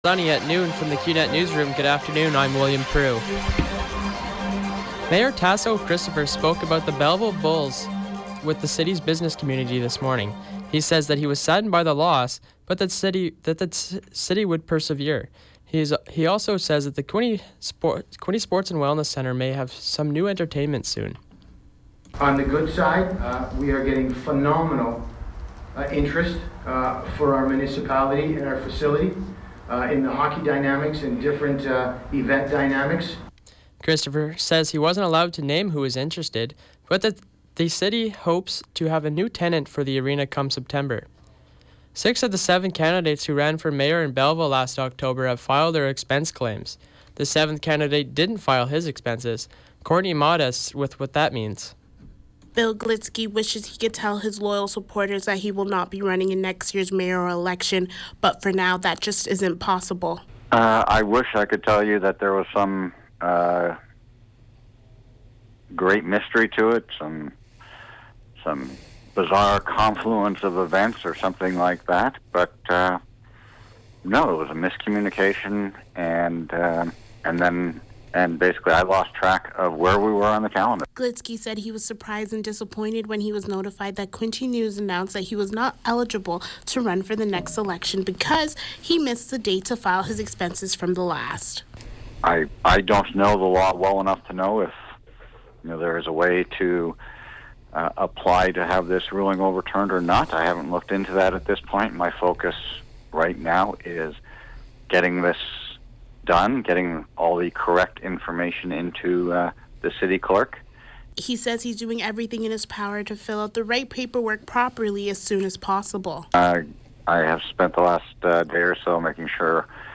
Mayor Taso Christopher spoke to members of the chamber of commerce about what will happen to the Yardmen Arena once the Belleville Bulls leave town.
PODCAST: Belleville mayor speaks with Chamber of Commerce about the future of the Yardmen Arena